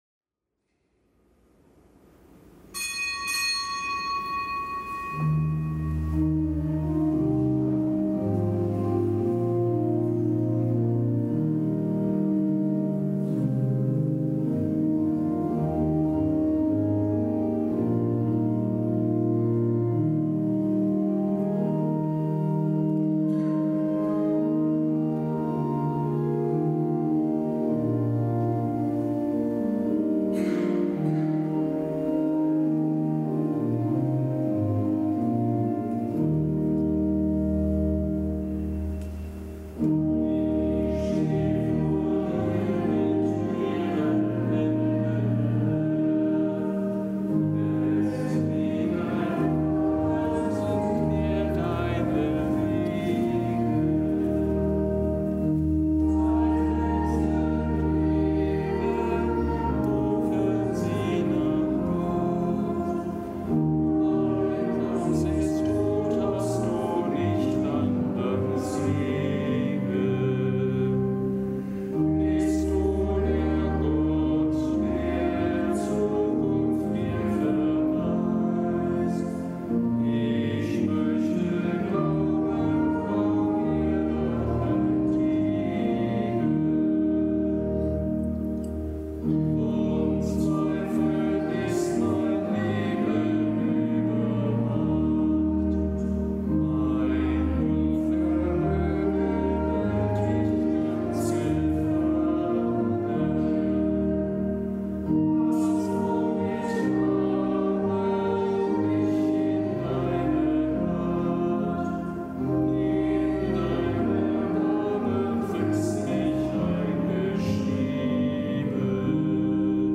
Kapitelsmesse am Mittwoch der vierundzwanzigsten Woche im Jahreskreis
Kapitelsmesse aus dem Kölner Dom am Mittwoch der vierundzwanzigsten Woche im Jahreskreis, nichtgebotener Gedenktag der Heiligen Hildegard von Bingen, Jungfrau, Kirchenlehrerin, Gründerin von Rupert